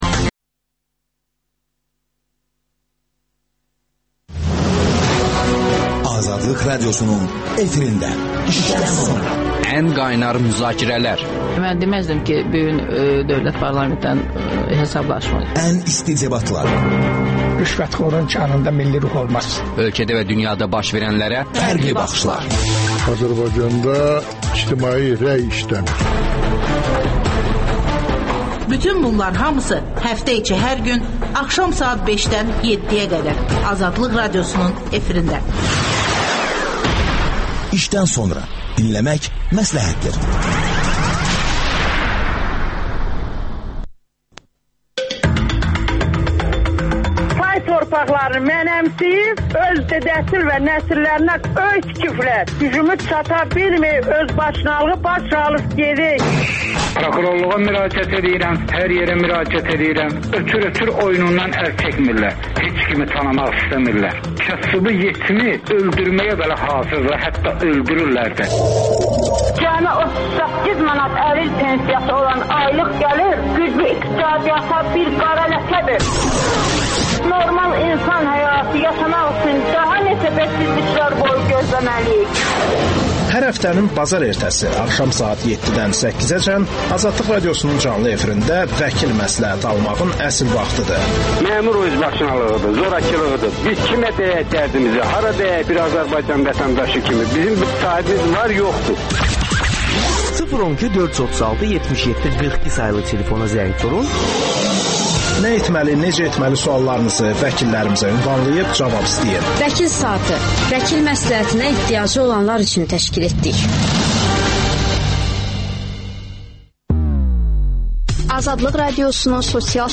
siyasi şərhçilər